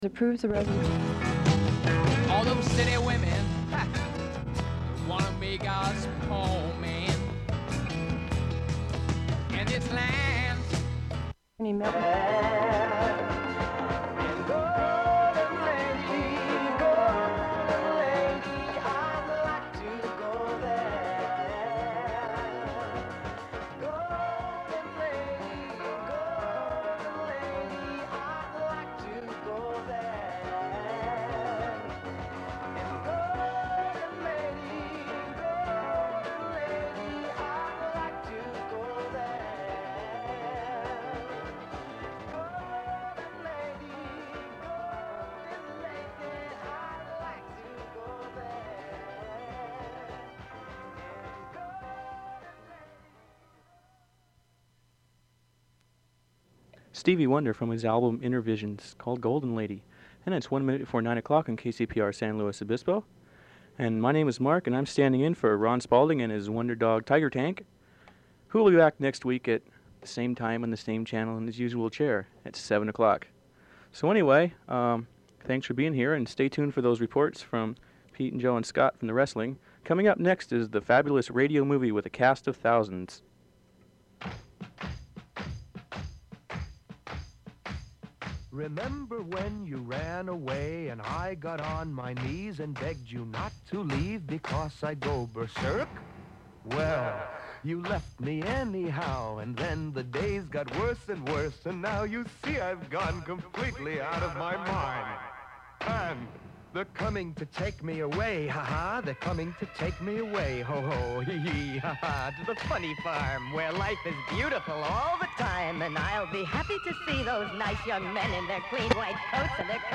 Download audio Metrics 41 views 17 downloads Citations: EndNote Zotero Mendeley Audio [KCPR broadcast compilation], 1976 Compilation of several KCPR broadcast programs, which begins with music and an introduction, which transitioned into business news. After, there was a live broadcast at the Cal Poly wrestling match. This followed with more music and dialogue, while also checking back to the wrestling match to know the final information.
Form of original Open reel audiotape